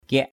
/kiaʔ/ aiek: ‘akiak’ ak`K